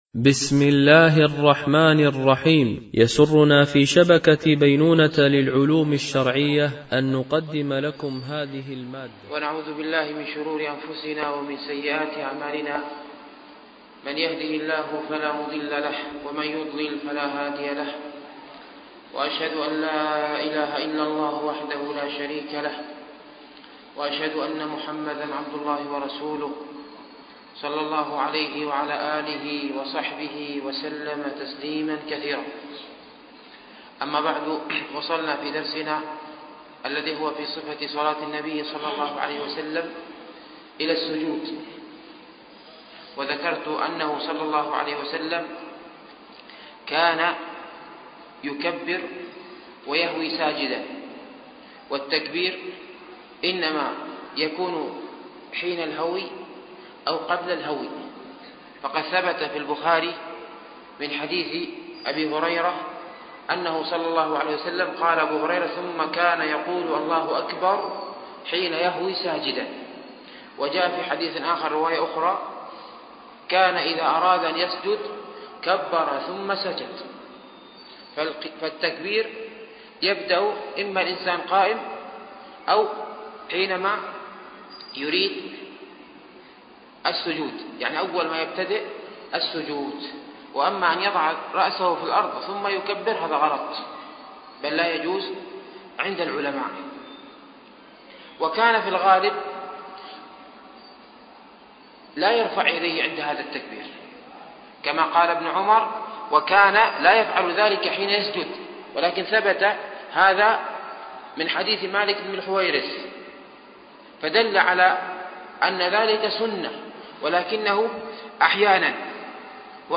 فقه الصلاة ـ الدرس الثاني عشر